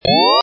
Descarga de Sonidos mp3 Gratis: alarma 9.